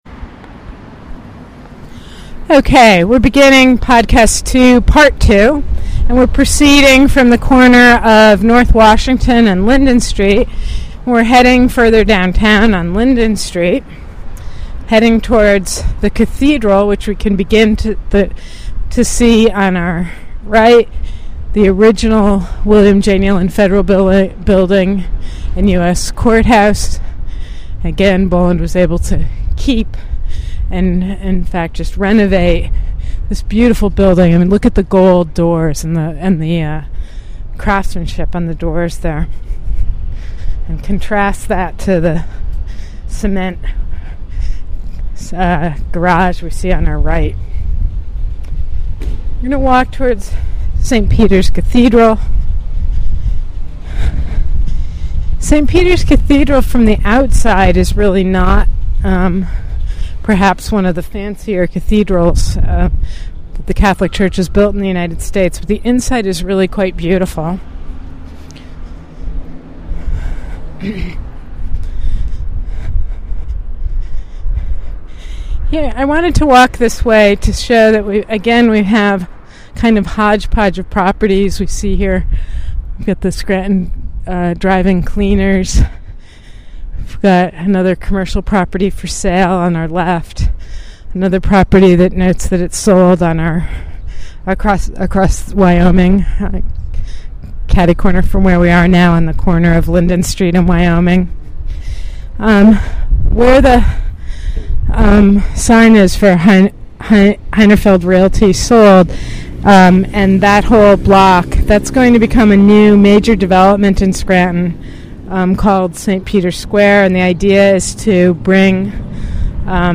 "philosophical" walking tour of downtown Scranton